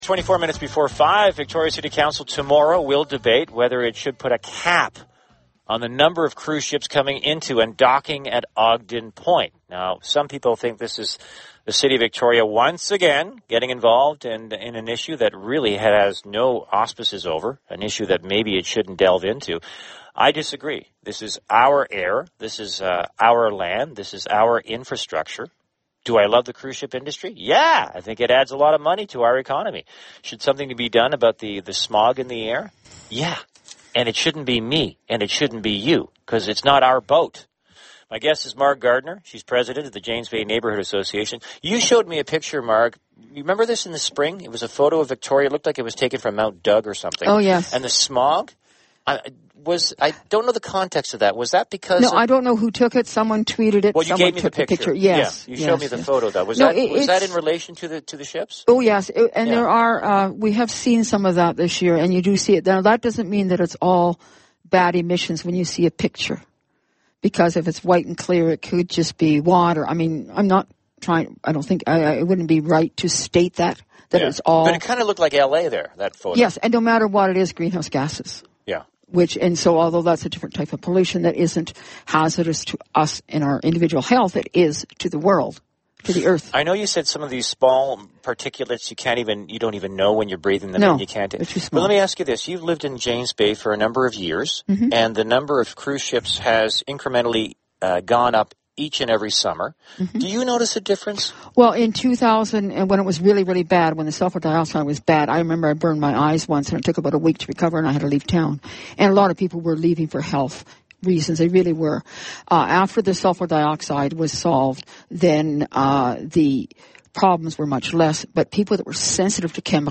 CFax 1070 interview